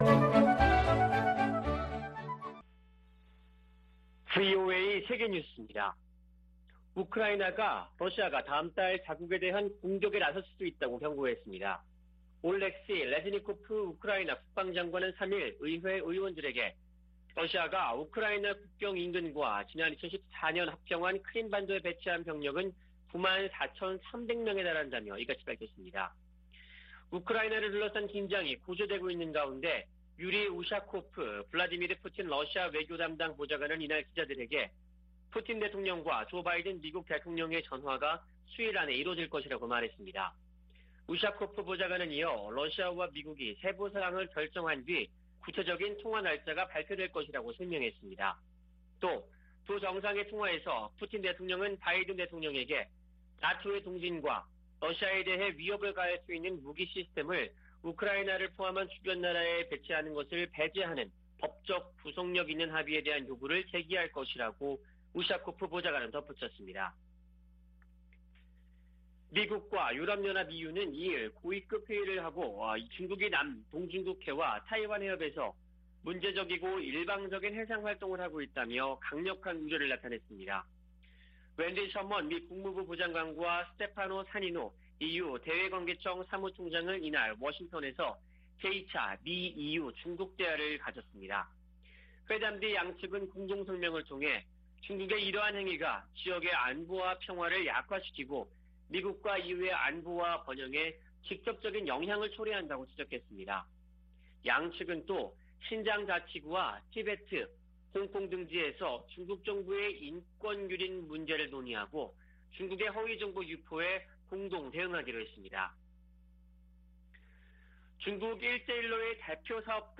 VOA 한국어 아침 뉴스 프로그램 '워싱턴 뉴스 광장' 2021년 12월 4일 방송입니다.